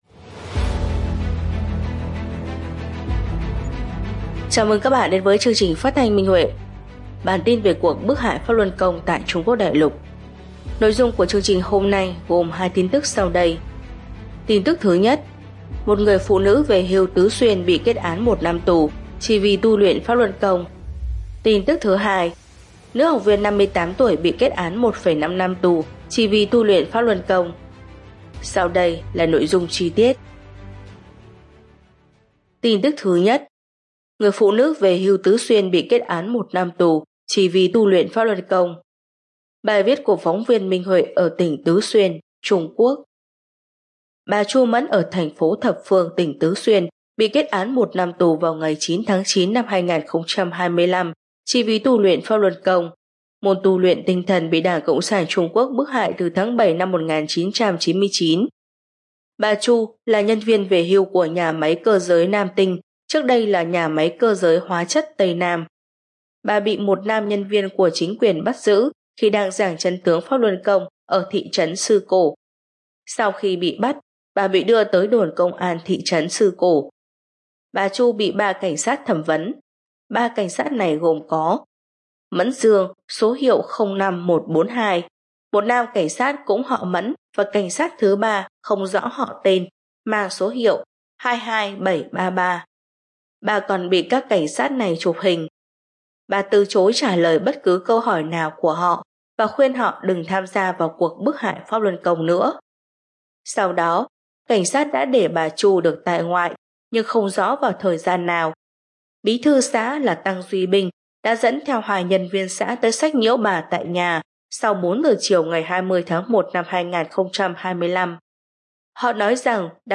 Chào mừng các bạn đến với chương trình phát thanh Minh Huệ.